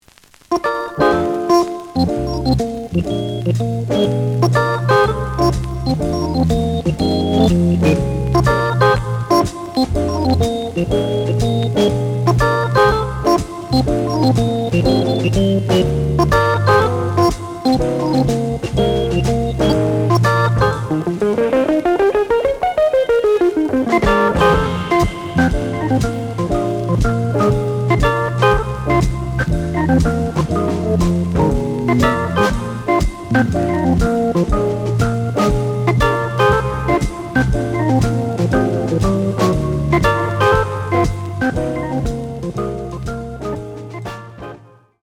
The audio sample is recorded from the actual item.
●Genre: Jazz Funk / Soul Jazz
Slight sound cracking on A side.